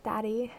Tags: GirlVoice Girl Voice Gamer GamerGirl Gamer Girl Girl